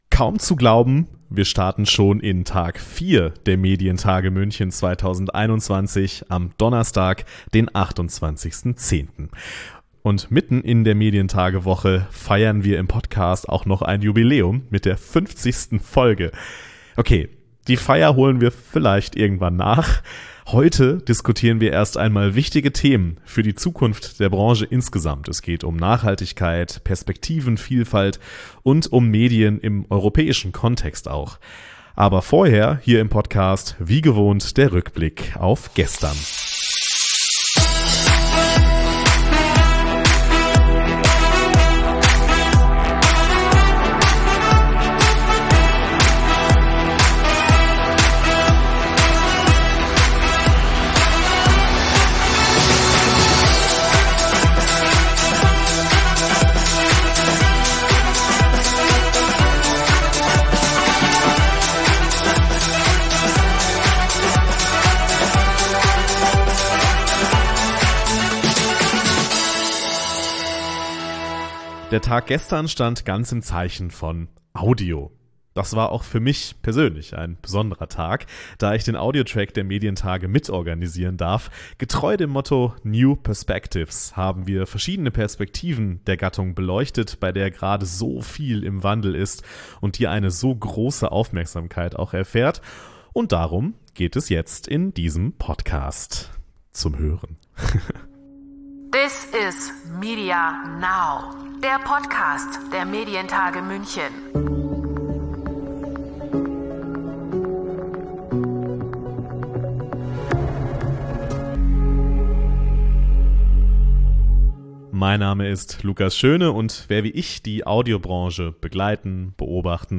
Es sind Expert:innen aus der Branche zu Gast, ihr hört Ausschnitte von Medienevents